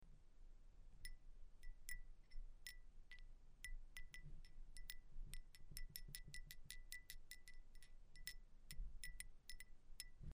Sound recordings of original Roman bells from Vindobona (ancient Vienna) and Avar pellet bells from the cemeteries Csokorgasse 1110 Wien and Wien-Liesing (1230 Wien).